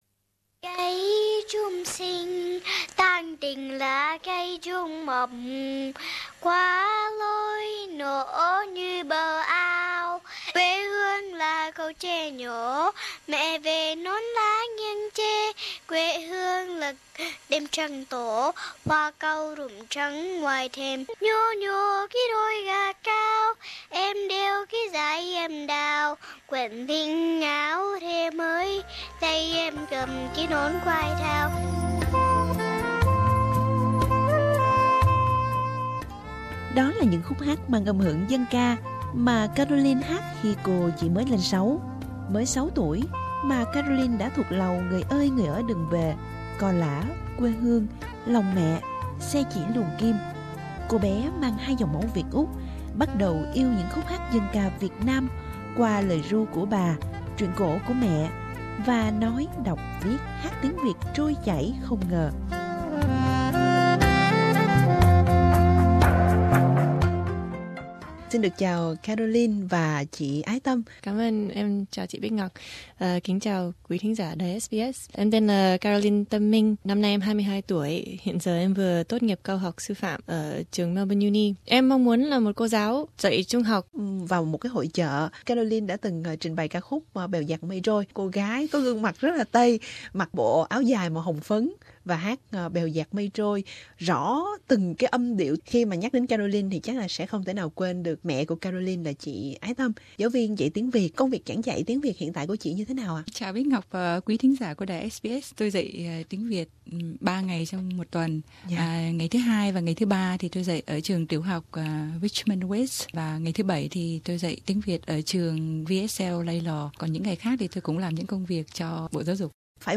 cô gái mang hai dòng máu Việt Úc hát dân ca quan họ trên đất Úc